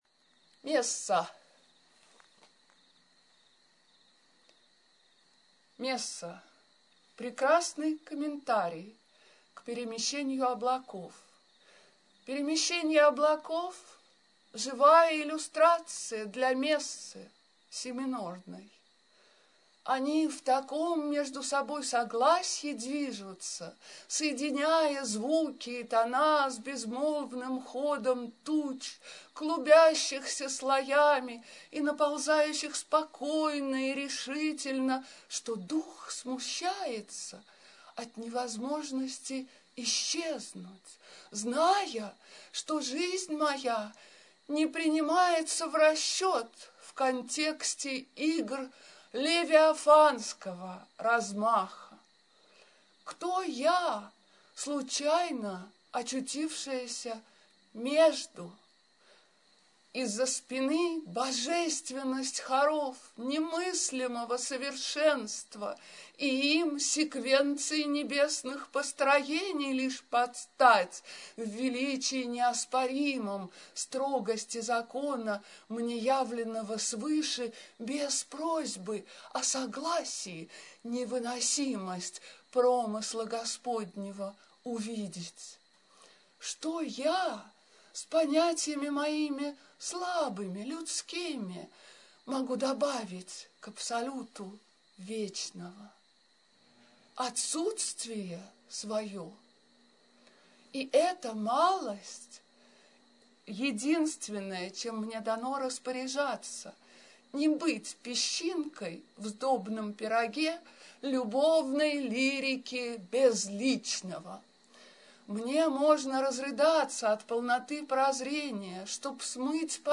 Авторское чтение: